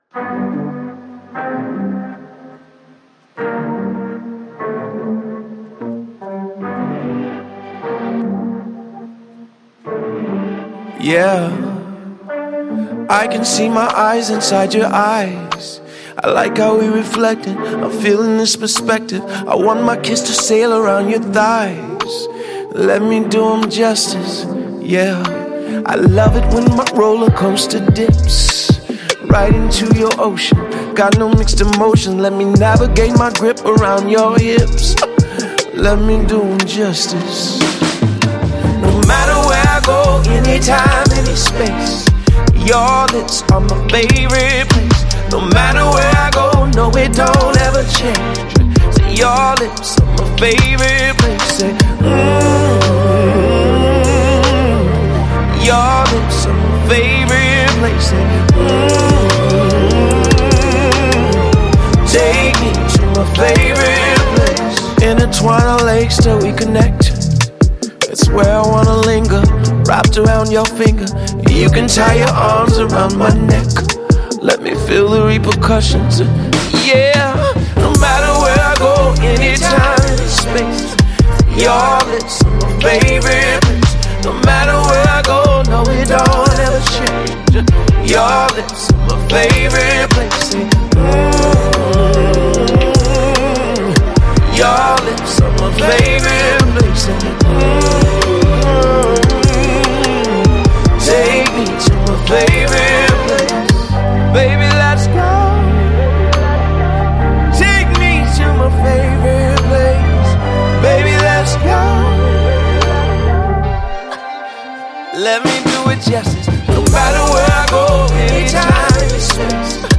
Genre R&B/Soul